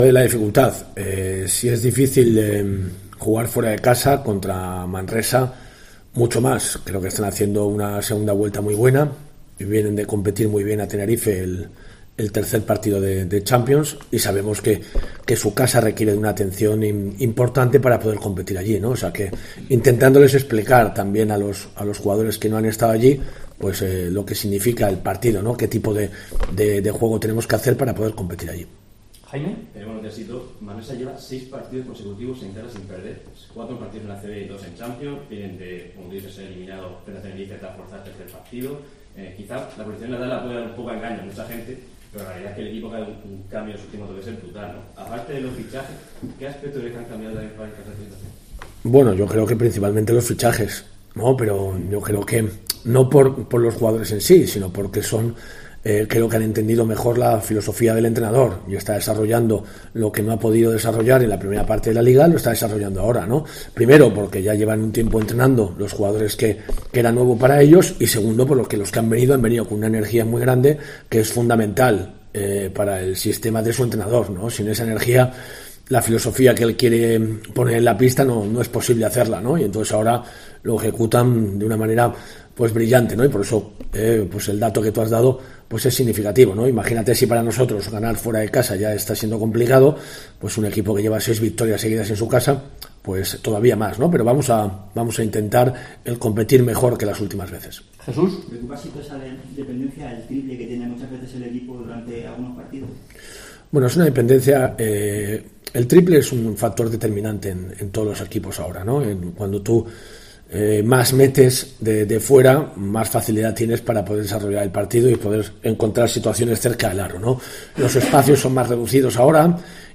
El técnico universitario habló este viernes en rueda de prensa a dos días de visitar al Baxi en partido de la vigésimo novena jornada del campeonato que comenzará a las doce y media del mediodía del domingo en el pabellón Nou Congost.